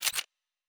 pgs/Assets/Audio/Sci-Fi Sounds/Weapons/Weapon 07 Reload 2.wav at master
Weapon 07 Reload 2.wav